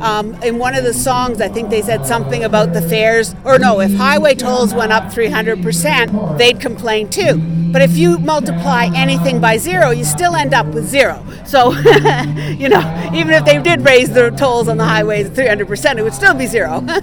And earlier today there was a kickoff rally protesting rising ferry fares and other hot topics surrounding BC Ferries services.